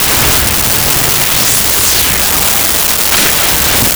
Flyby4
flyby4.wav